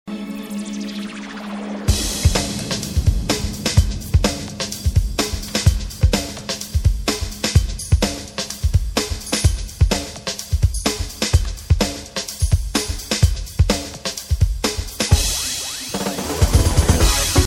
Рок-опера "Ники и Аликс"